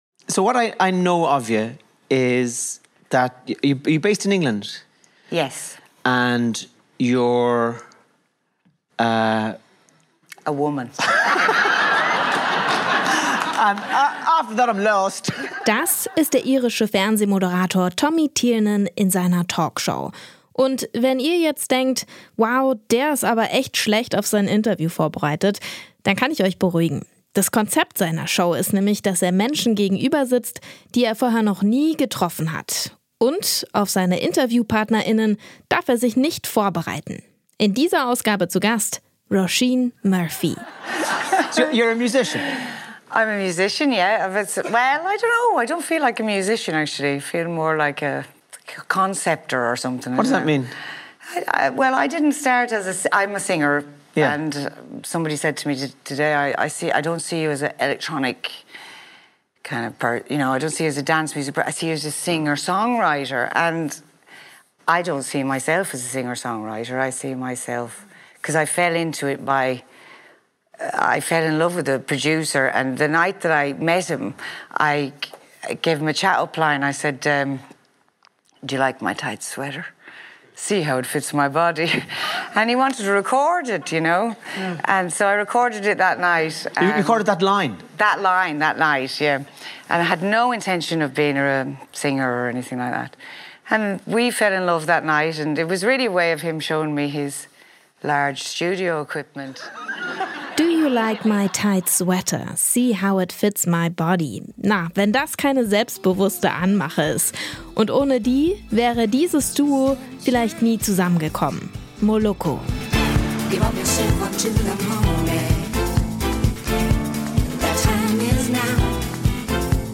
Im Popfilter hören wir ihre Musik.